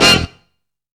ICY HIT.wav